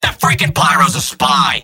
Robot-filtered lines from MvM. This is an audio clip from the game Team Fortress 2 .